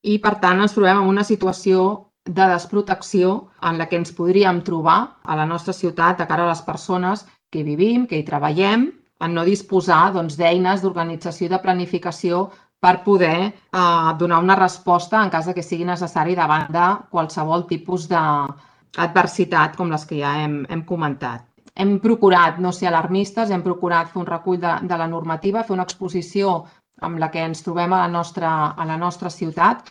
Helena Solà, portaveu ERC